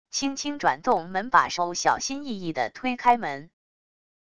轻轻转动门把手小心翼翼地推开门wav音频